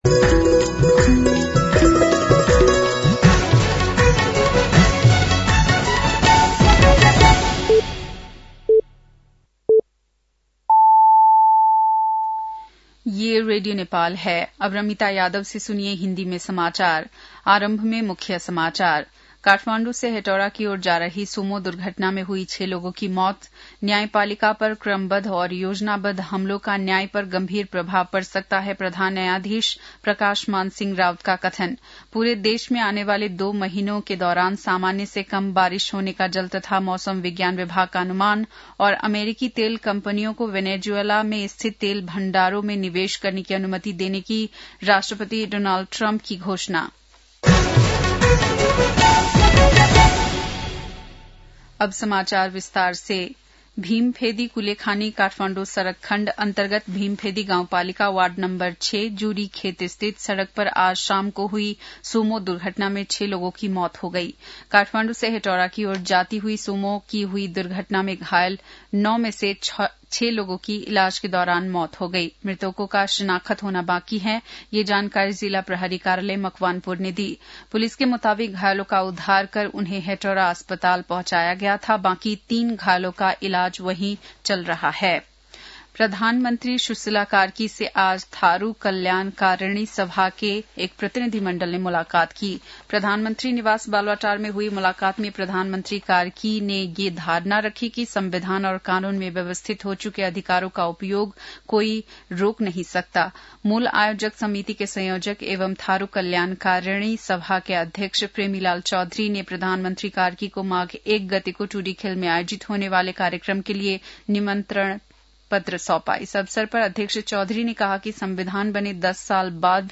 बेलुकी १० बजेको हिन्दी समाचार : २० पुष , २०८२
10-pm-hindi-news-9-20.mp3